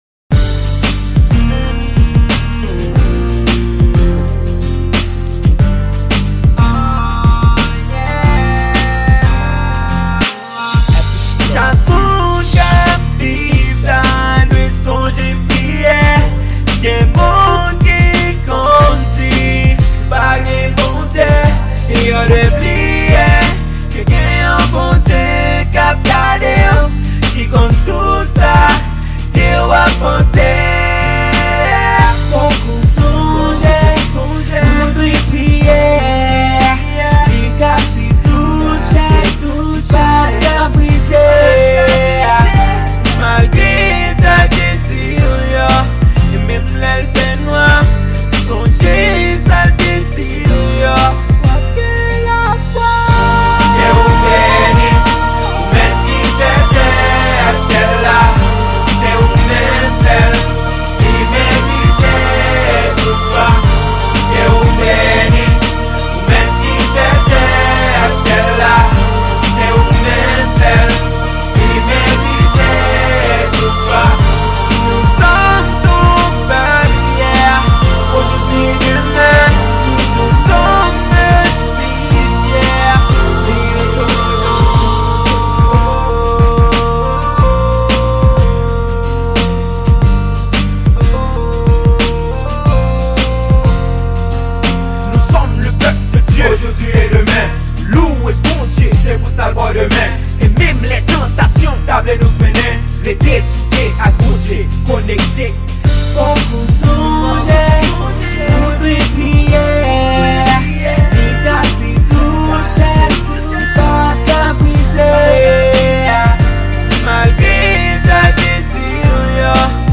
Genre: RAP GOSPEL.